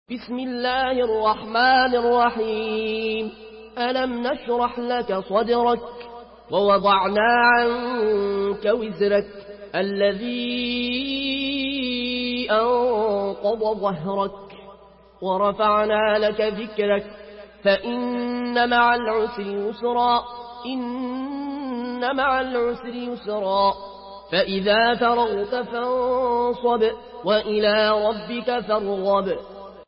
Murattal Warsh An Nafi From Al-Azraq way